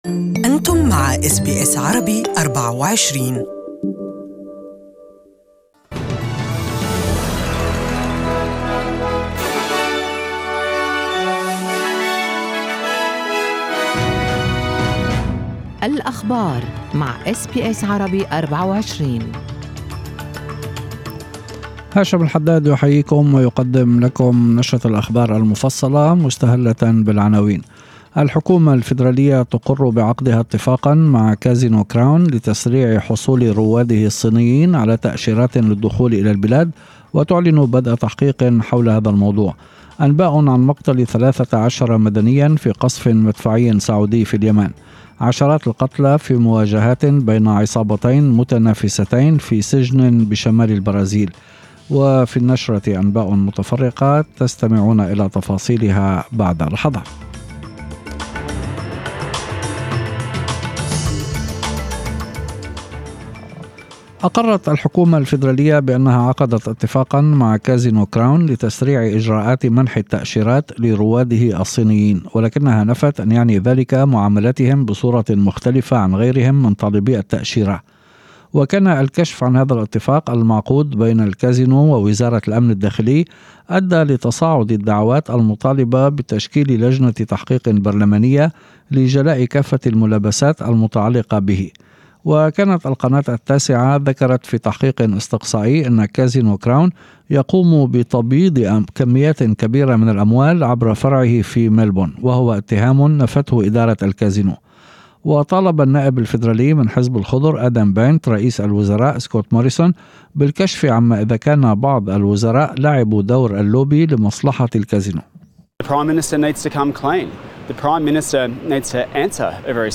Evening News:Australian government orders investigation into Crown Casino controversy